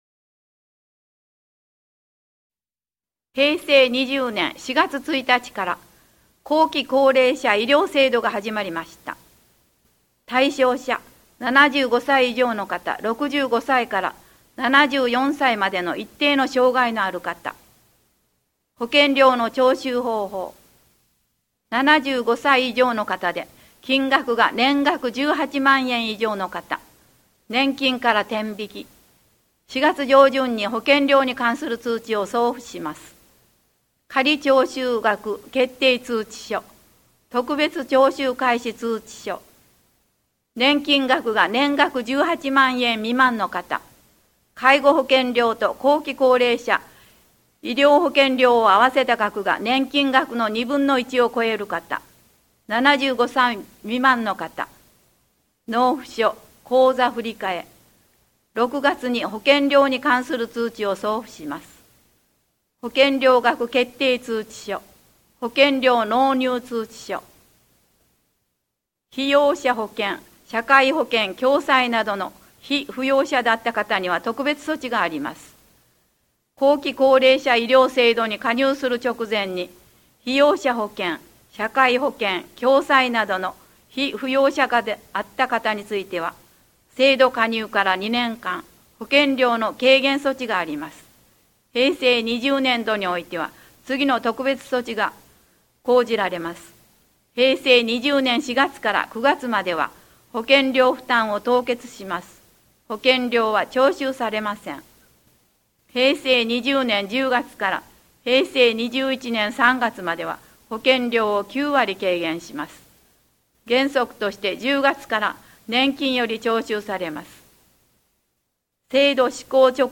また、音訳ボランティア「エポカル武雄フレンズ」のご協力により、音読データをMP3形式で提供しています。